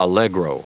Prononciation du mot allegro en anglais (fichier audio)
Prononciation du mot : allegro